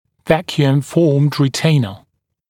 [‘vækjuəm fɔːmd rɪ’teɪnə] [-juːm][‘вэкйуэм фо:мд ри’тэйнэ] [-йу:м]ретенционная капа, изготовленная вакуумным методом